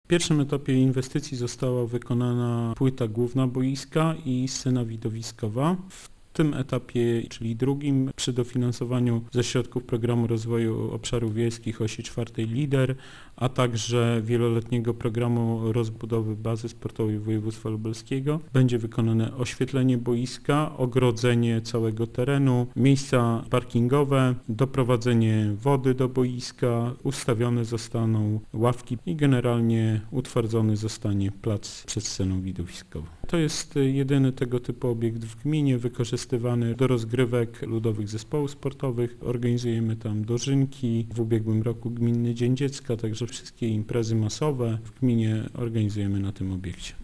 - Jest to drugi etap inwestycji na którą pozyskaliśmy środki zewnętrzne - mówi wójt Zenon Stefanowski: